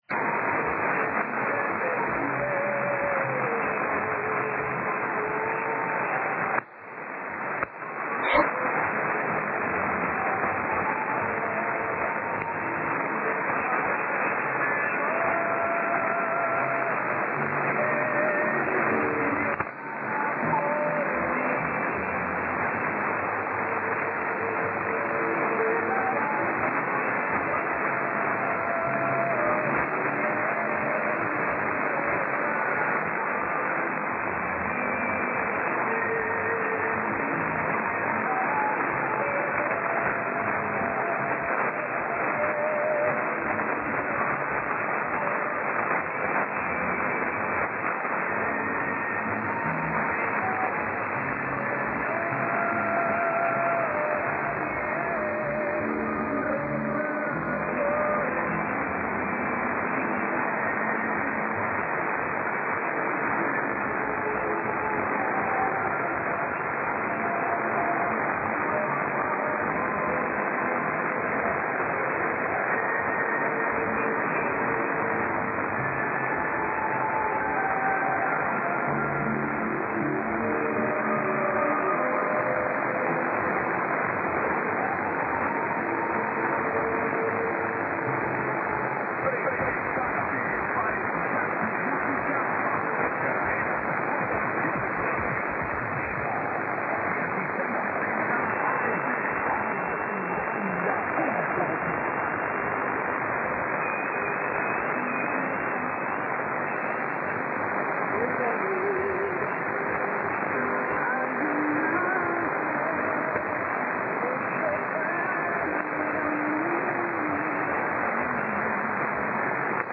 Che sensazione ascoltare, sotto un cielo già illuminato, qualche bell'annuncio (per esempio
qui e qui) dalla Georgia, uno stato americano tutt'altro che facile, con Viva 16-70 WVVM e il suo tipico format messicano.